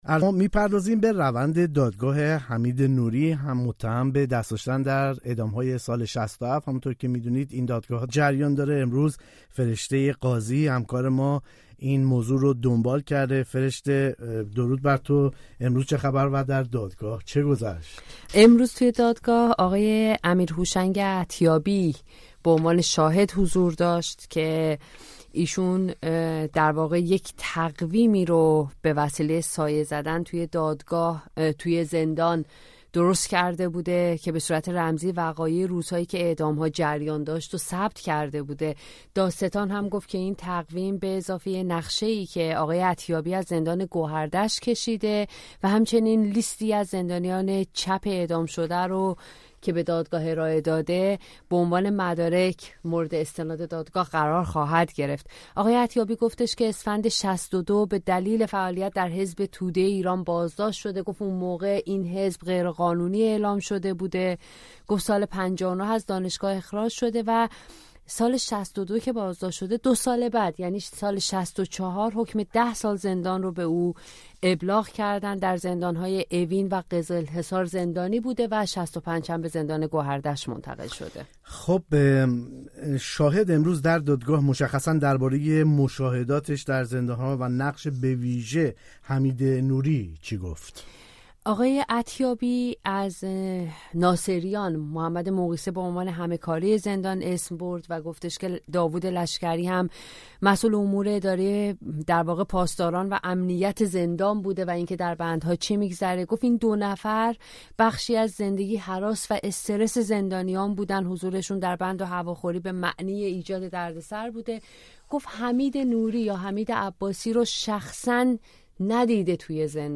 گزارشی از دادگاه حمید نوری در روز دوشنبه چهارم بهمن